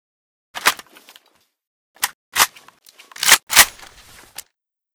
saiga_reload_empty.ogg